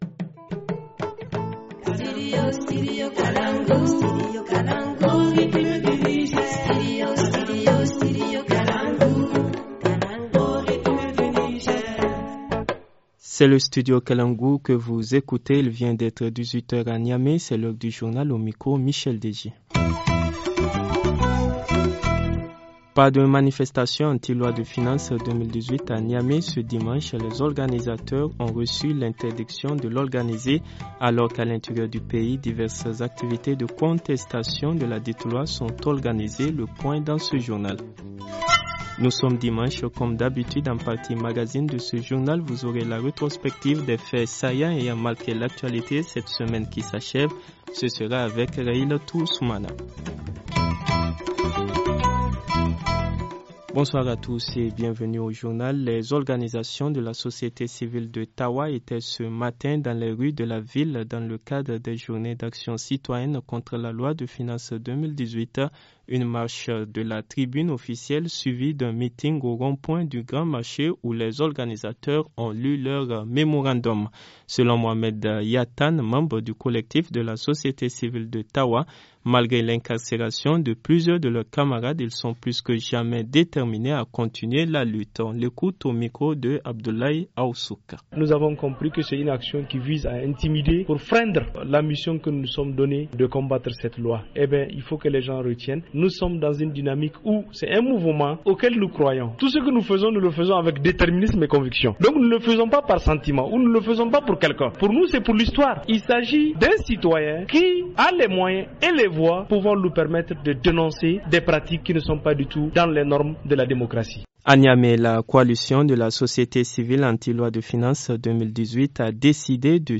Journal du 22 avril 2018 - Studio Kalangou - Au rythme du Niger